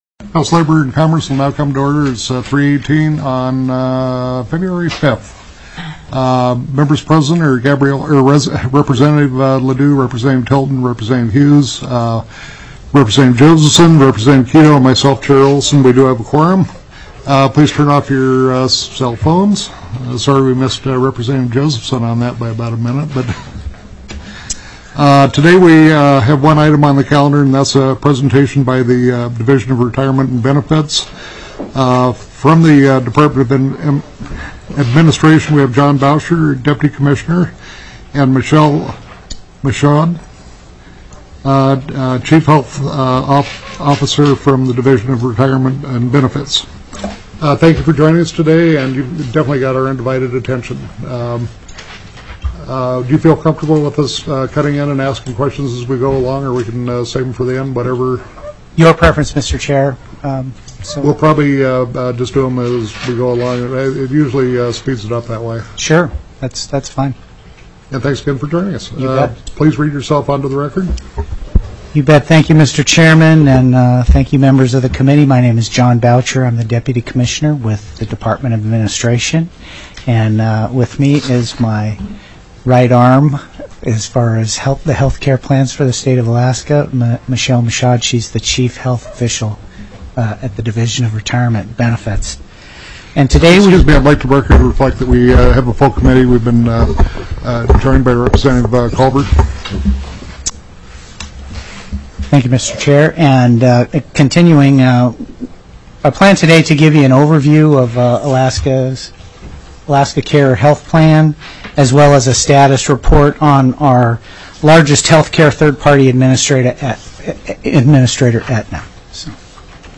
02/05/2016 03:15 PM House LABOR & COMMERCE
The audio recordings are captured by our records offices as the official record of the meeting and will have more accurate timestamps.